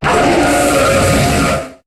Cri de Kyurem Blanc dans Pokémon HOME.